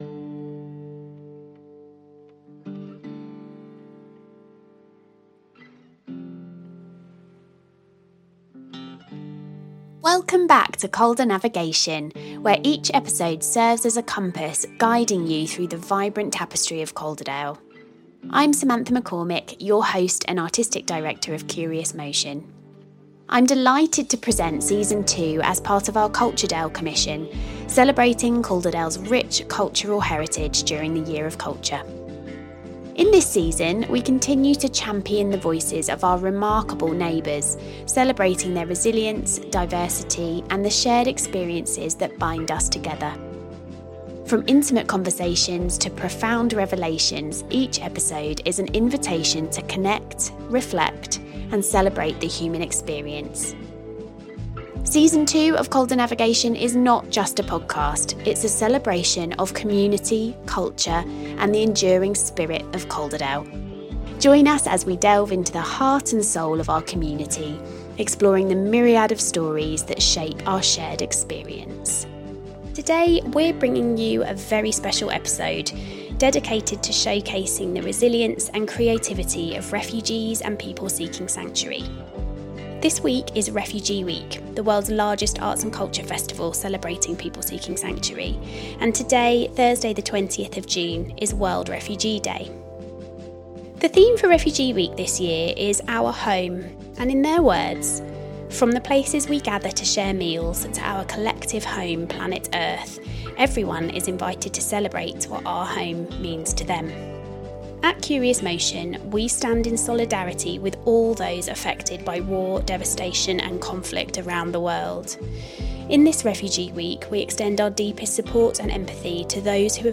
In this episode we’re showcasing the resilience and creativity of refugees and people seeking sanctuary, who have very kindly agreed to talk to us about what ‘home’ means to them. Today you'll hear beautiful pieces of writing and poetry alongside short interviews.
The podcast episode you are about to hear features the courageous voices of refugees currently living here in Calderdale.